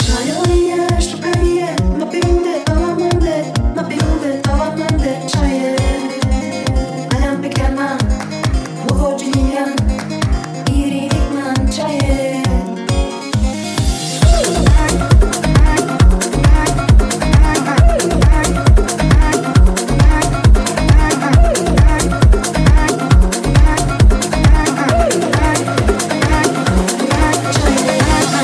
Genere: house,salsa,tribal,afro,tech,remix,hit